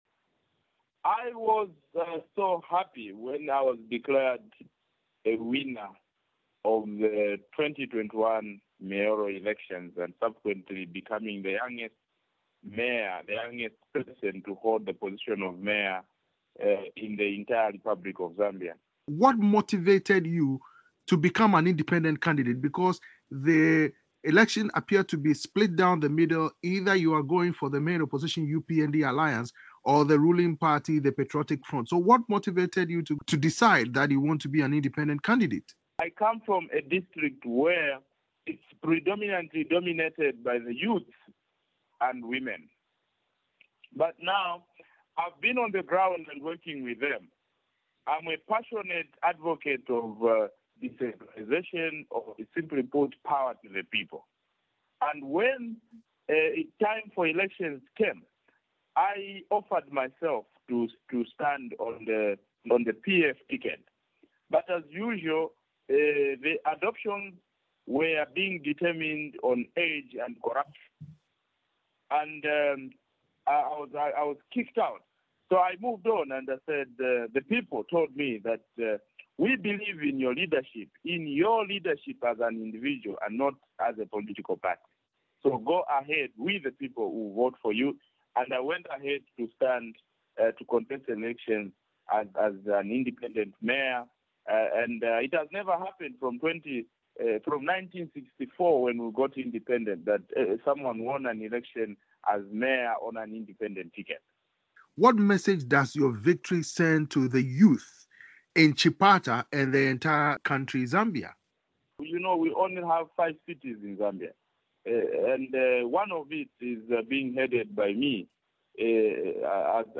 At 27 years old, George Mwanza is the youngest candidate ever to be elected mayor of a city in Zambia. For Nightline Africa